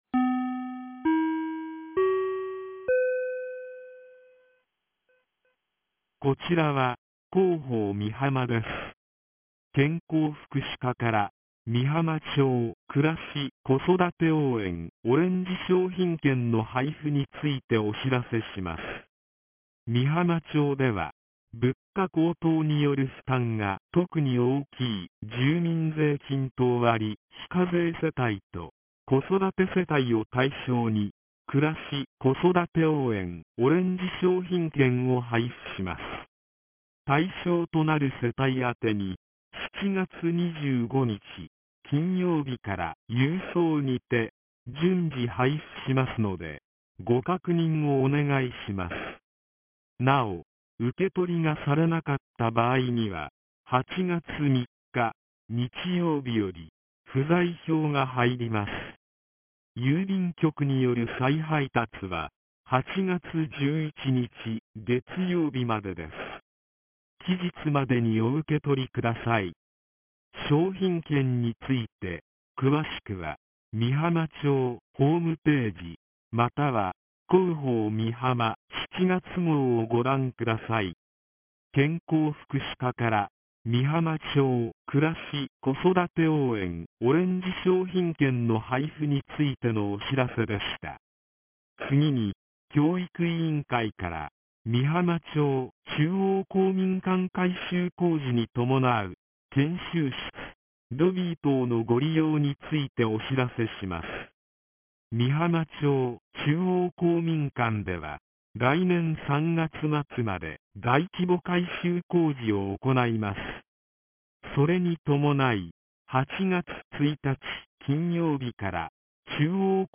■防災行政無線情報■
放送音声